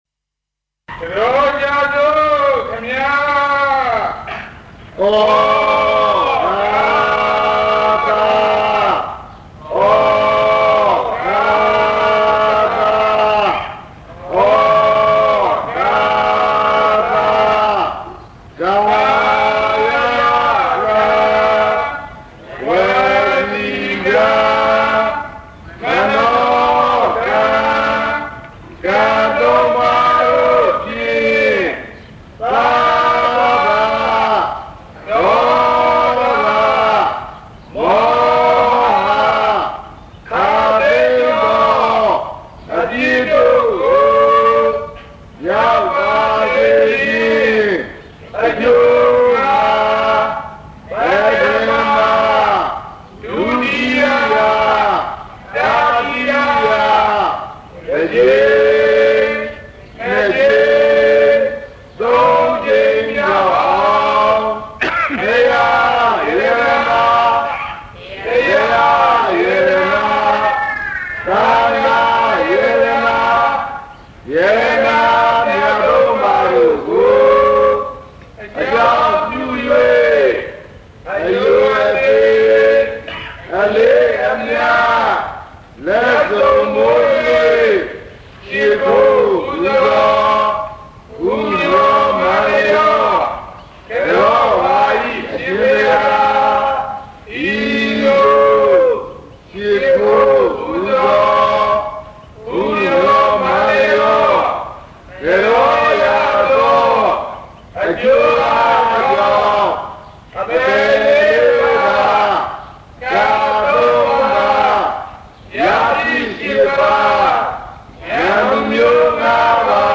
Dhamma Talk